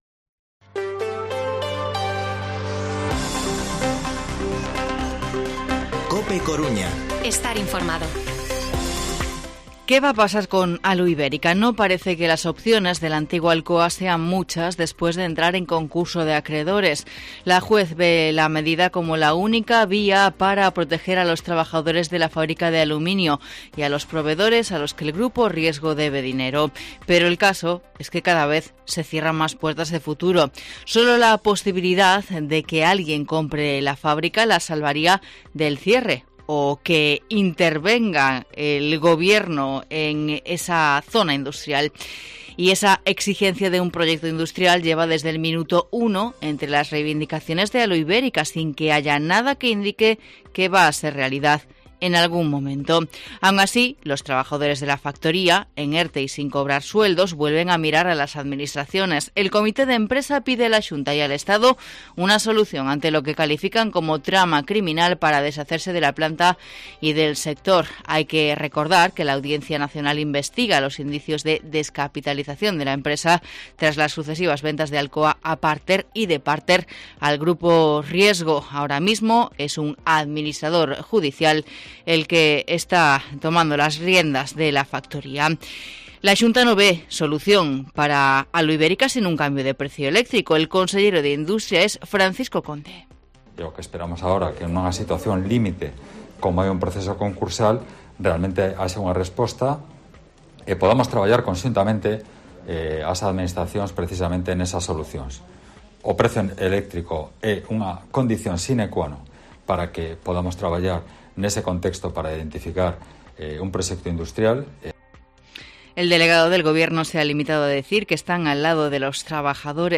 Informativo Mediodía COPE Coruña jueves, 25 de noviembre de 2021 14:20-14:30